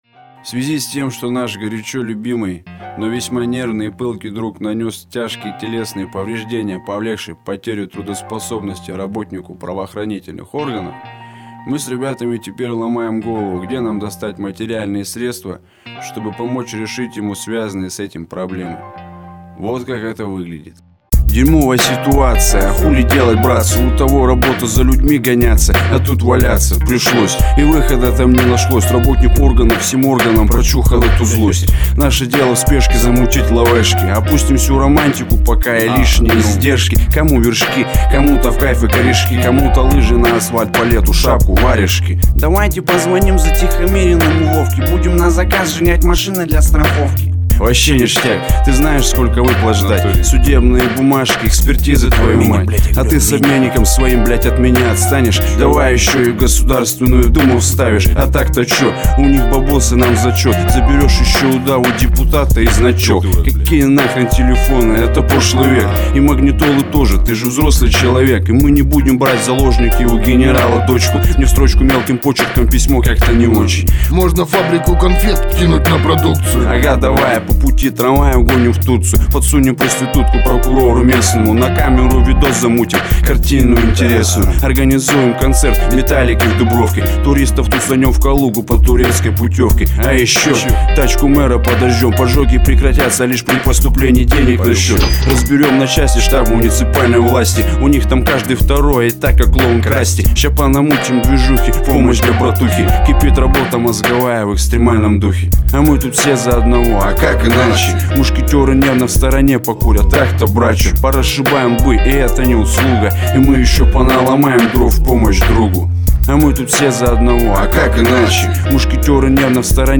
Жанр:Рэп